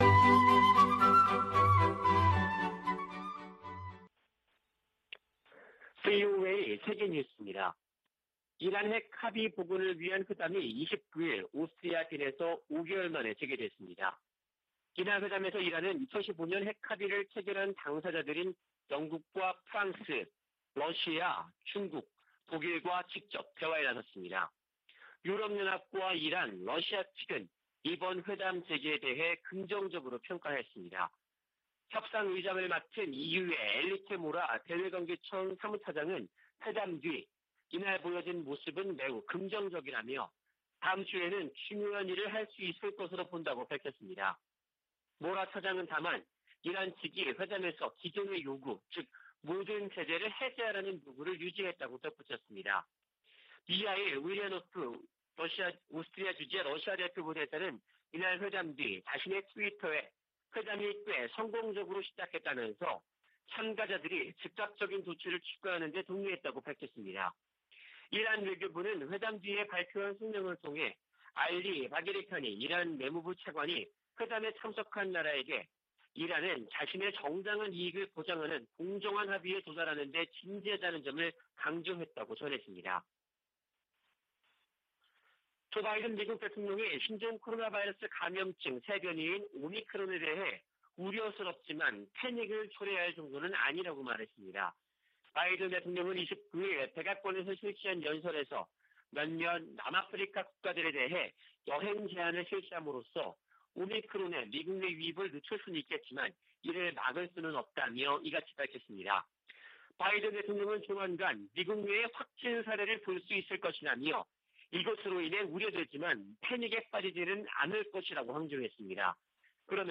VOA 한국어 아침 뉴스 프로그램 '워싱턴 뉴스 광장' 2021년 11월 30일 방송입니다. 북한이 신종 코로나바이러스 감염증의 새로운 변이종인 ‘오미크론’의 등장에 방역을 더욱 강화하고 있습니다. 북한의 뇌물 부패 수준이 세계 최악이라고, 국제 기업 위험관리사가 평가했습니다. 일본 정부가 추경예산안에 68억 달러 규모의 방위비를 포함시켰습니다.